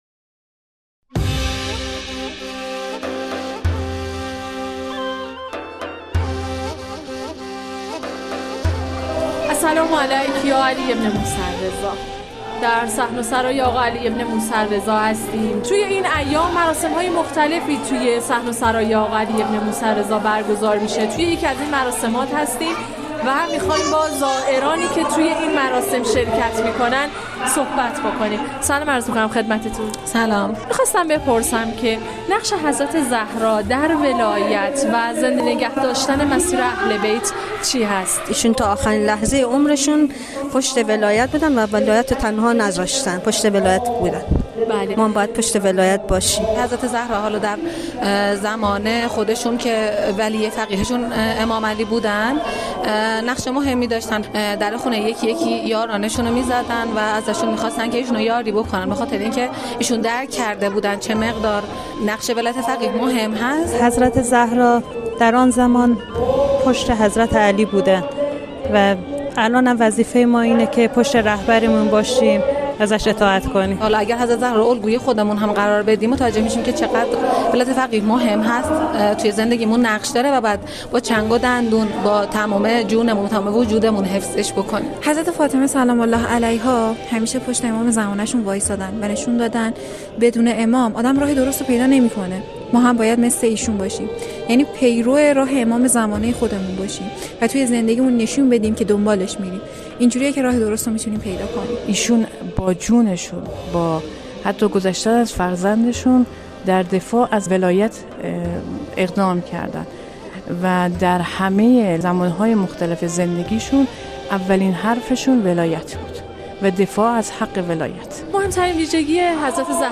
ویژگی‌های حضرت زهرا(س) از نگاه زائران حرم رضوی در مراسم «عطر یاس و یاد شهدا»/ گزارش رادیویی
زائران حرم مطهر رضوی در ویژه برنامه دهه فاطمیه، حضرت زهرا(س) را به‌عنوان الگویی از صبر و استقامت، پیروی از ولایت، عفاف و خویشتن‌داری ستودند. این مراسم با عنوان «عطر یاس و یاد شهدا» هر شب از شنبه به مدت پنج شب، ساعت ۱۷ تا ۲۱ در صحن قدس، حسینیه شهدا برگزار می‌شود.